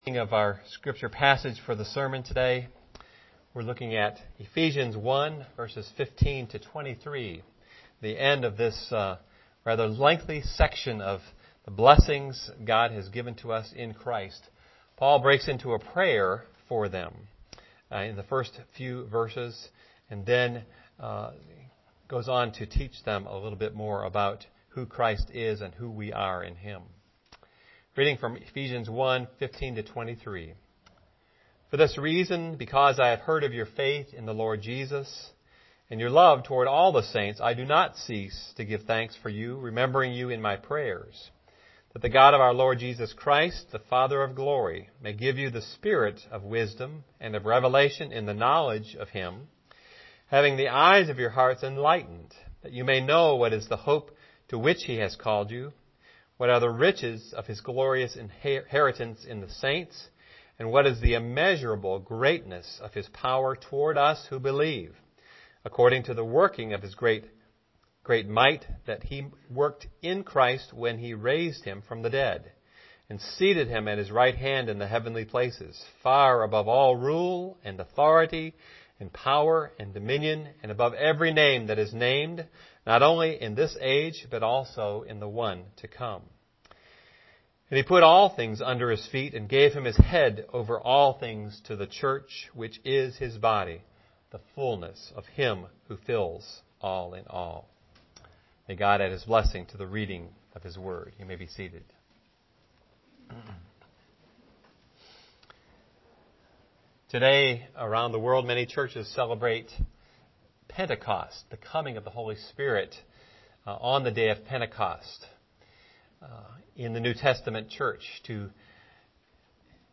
Home › Sermons › Sermons by Year › 2012 › Growing in the Knowledge of Christ (Ephesians 1:15-23)